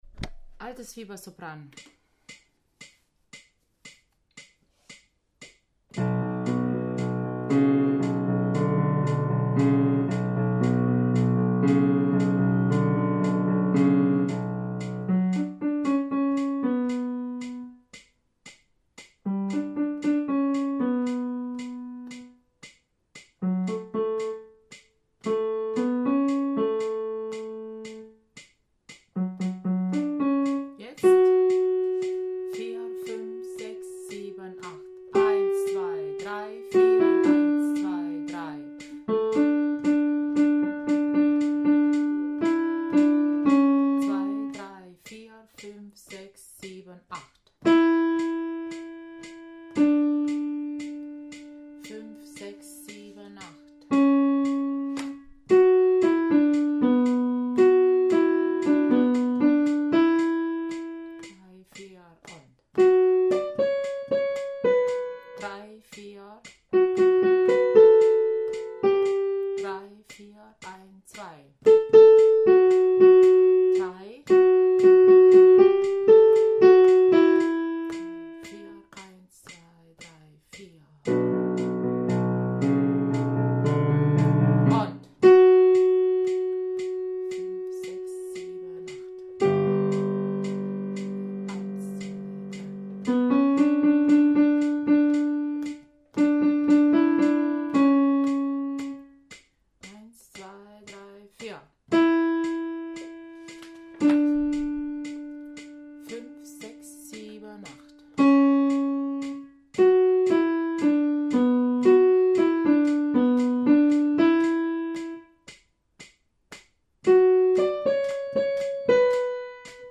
Altes-Fieber-Sopran.mp3